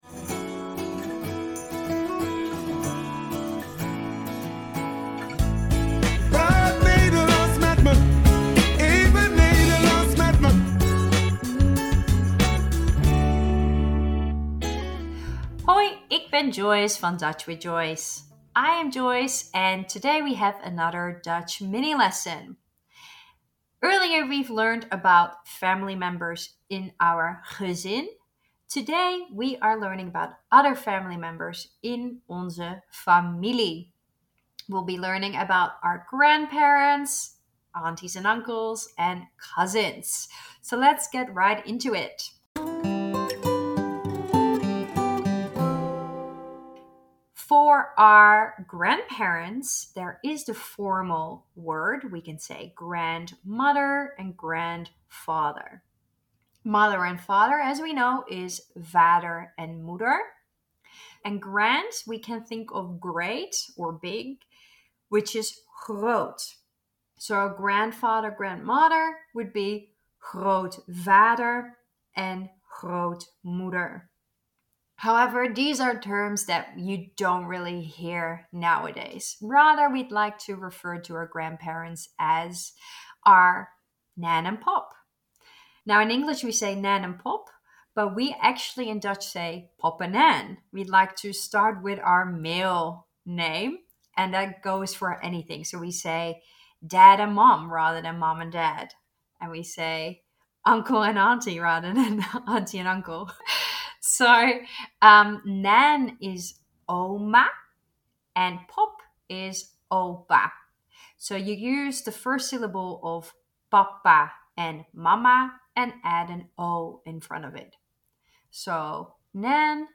In this podcast series you will learn a few Dutch words and pronunciations every week in a few minutes. The lessons are in English